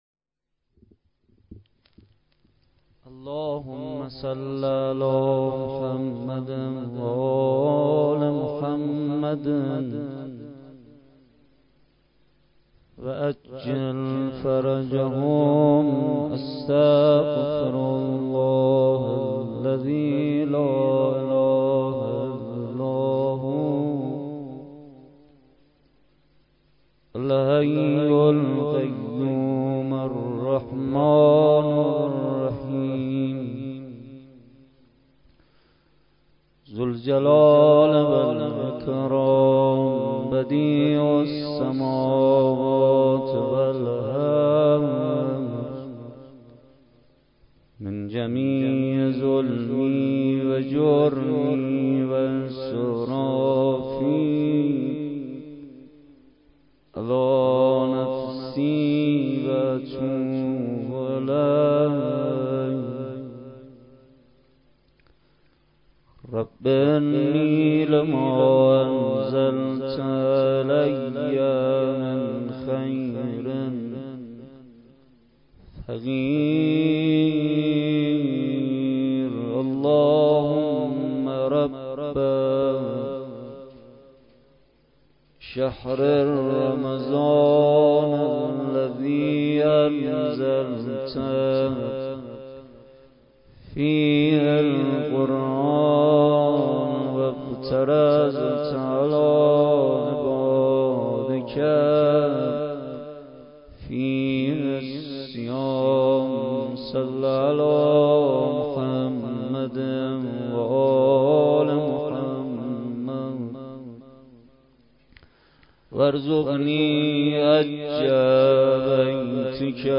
شب یازدهم ماه رمضان با مداحی کربلایی محمدحسین پویانفر در ولنجک – بلوار دانشجو – کهف الشهداء برگزار گردید.
دعا ومناجات روضه لینک کپی شد گزارش خطا پسندها 0 اشتراک گذاری فیسبوک سروش واتس‌اپ لینکدین توییتر تلگرام اشتراک گذاری فیسبوک سروش واتس‌اپ لینکدین توییتر تلگرام